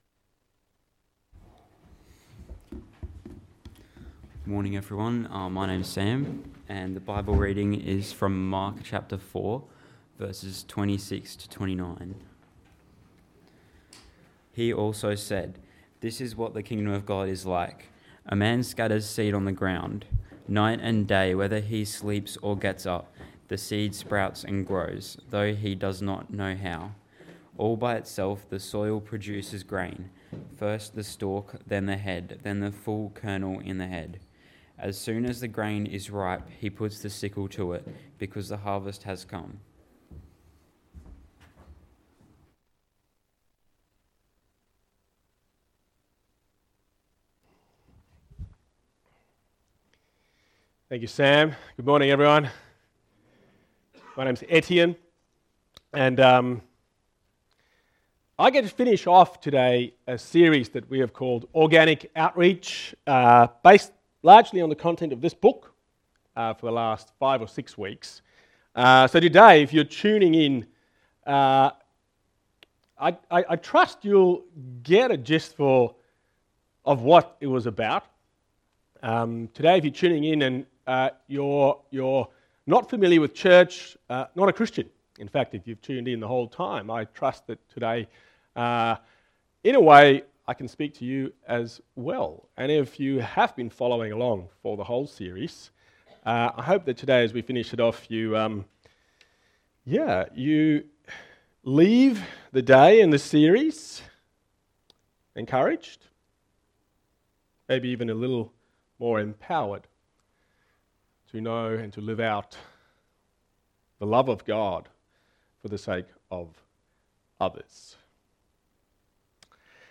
Text: Mark 4: 26-29 Sermon